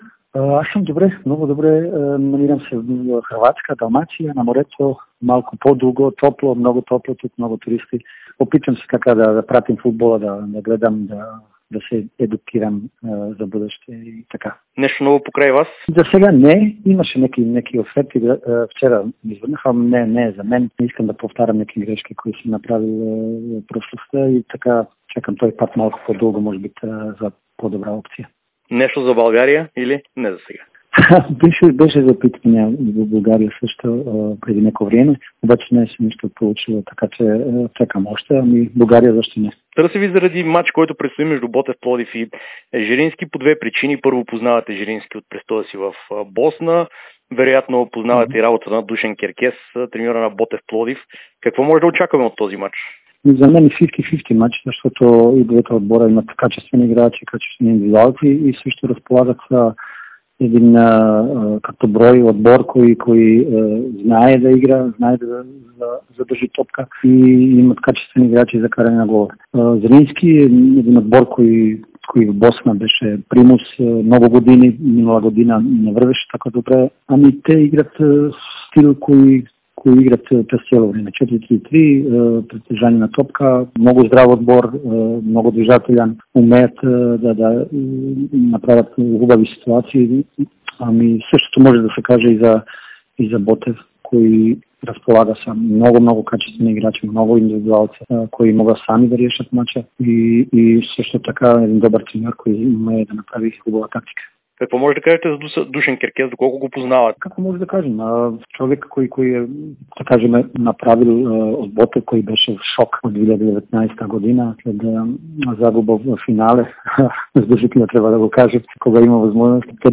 Бившият треньор на ЦСКА и Локомотив Пловдив – Бруно Акрапович, сподели впечатленията си от развитието на двата отбора в специално интервю пред Дарик и dsport.